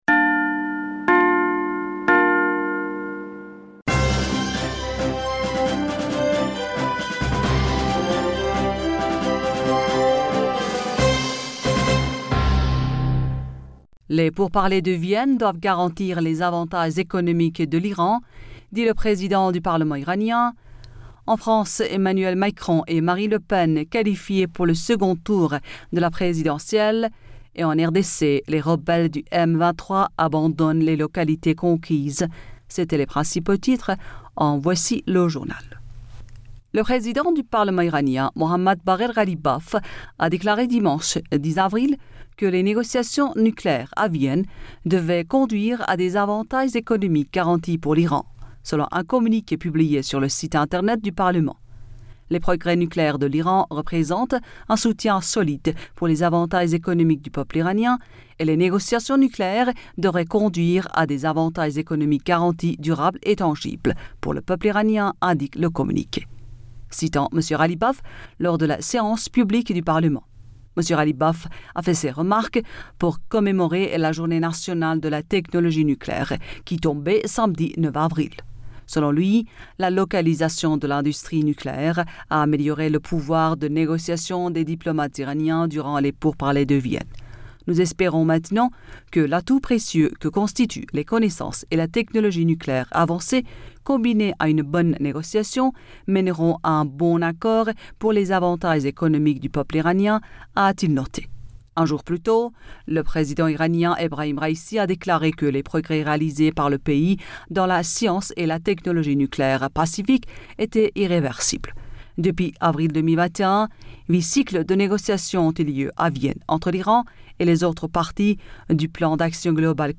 Bulletin d'information Du 11 Avril 2022